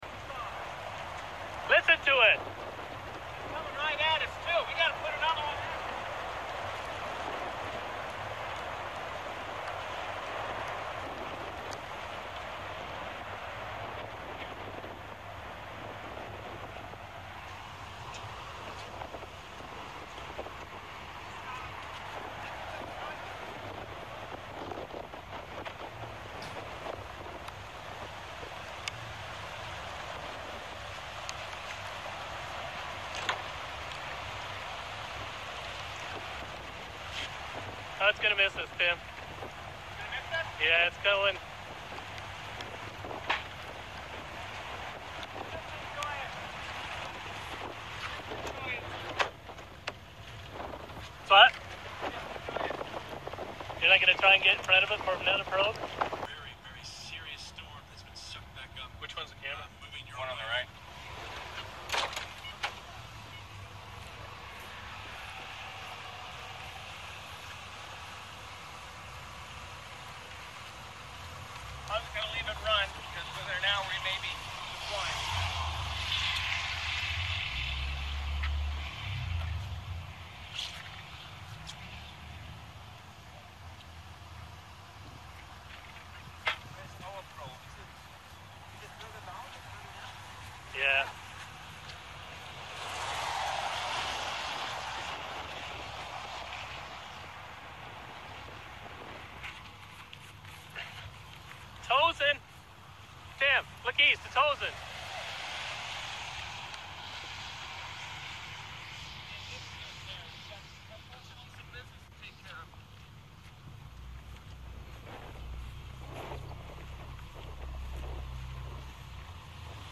Manchester, SD Tornado June Sound Effects Free Download